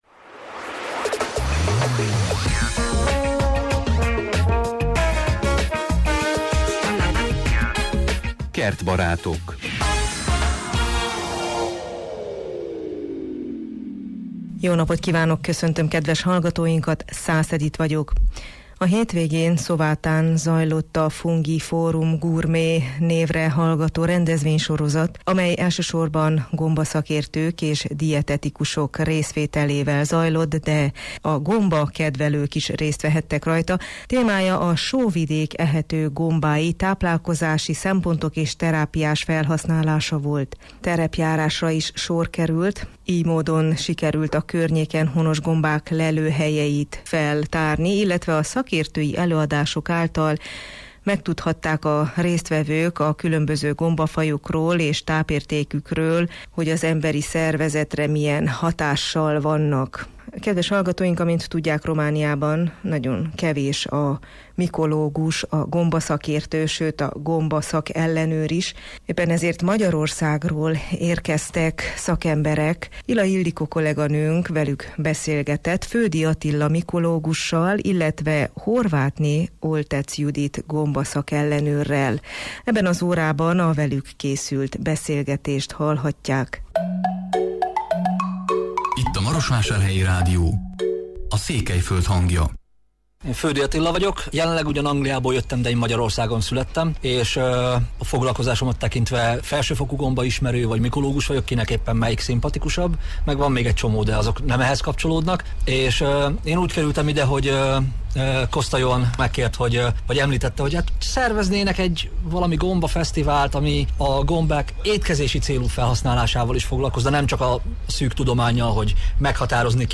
beszélgetett a két gombaszakértővel a Kertbarátok műsor keretében.